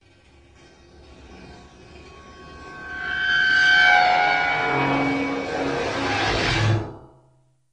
Metal Chalkboard Squeal Light to Hard